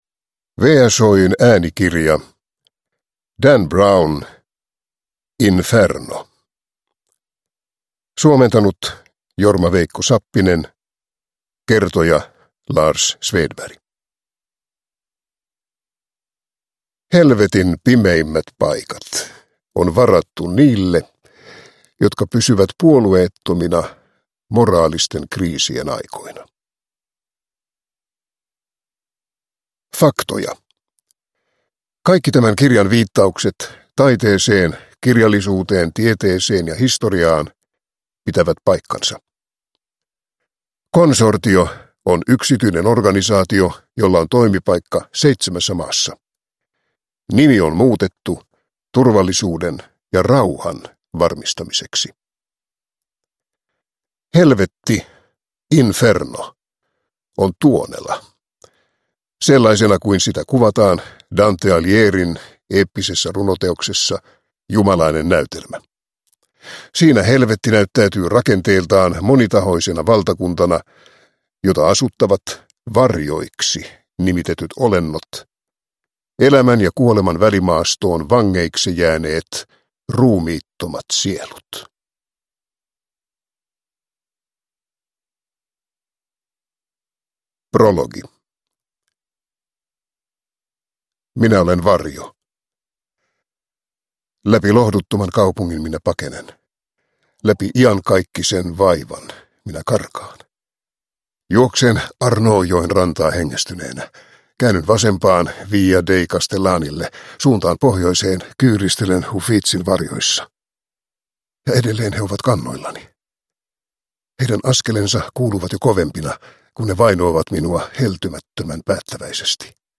Inferno – Ljudbok – Laddas ner